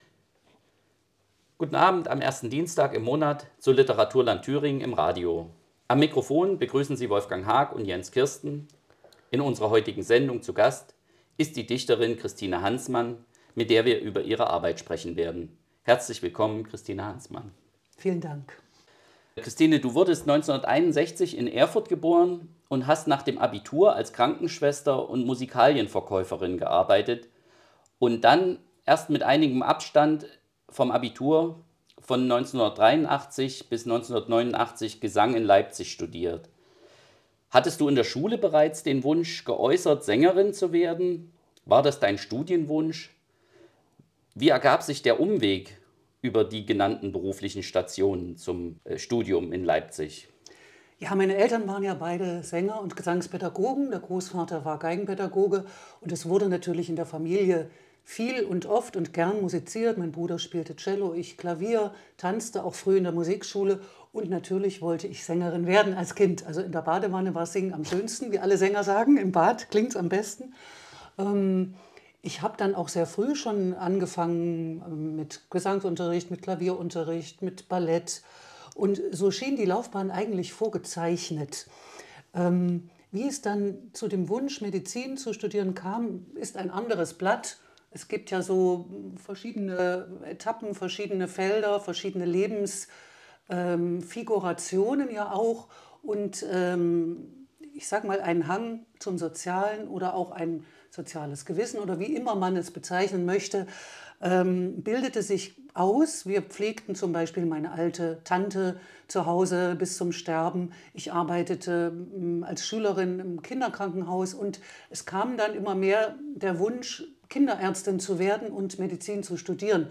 Sendung am Dienstag, dem 6. Dezember 2022, 22–23 Uhr auf Radio Lotte Weimar.